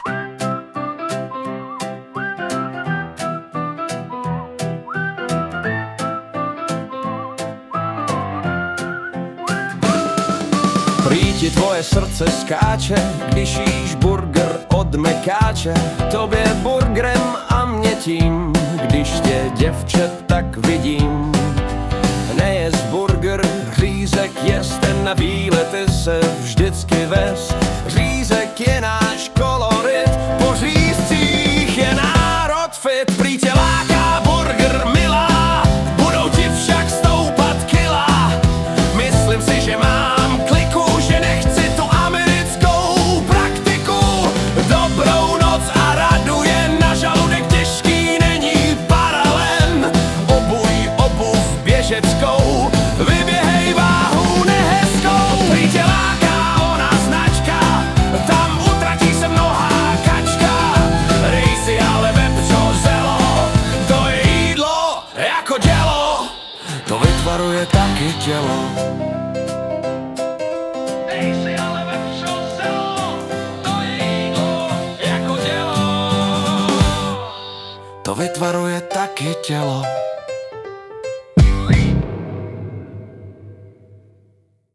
hudba, zpěv: AI